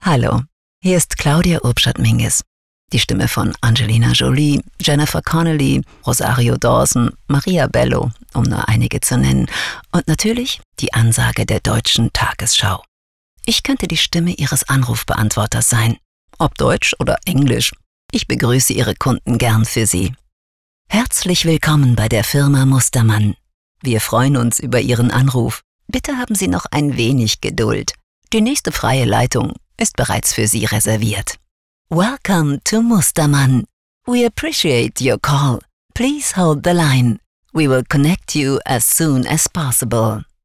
weibliche Sprecher - B - soundlarge audioproduktionen
Sprecher_Claudia-Urbschat-Mingues_Demo_Telefonansagen_2024.mp3